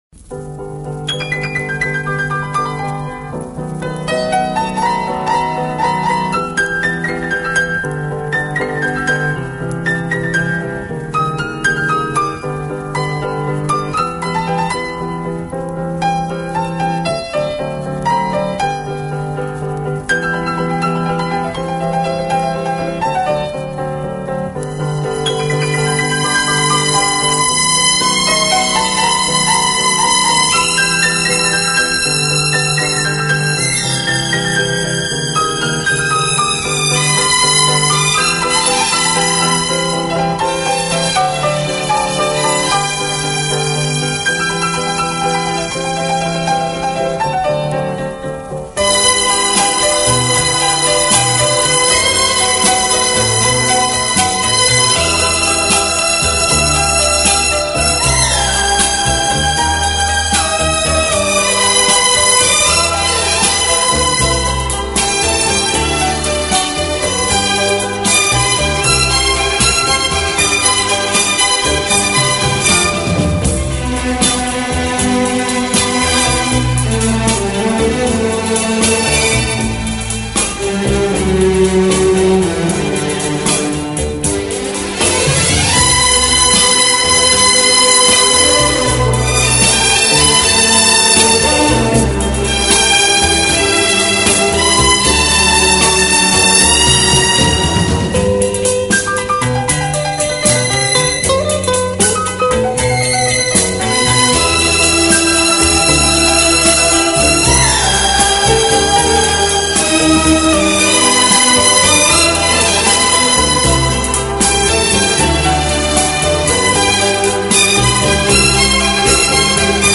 乐队以弦乐为中坚，演奏时音乐的处理细腻流畅，恰似一叶轻舟，随波荡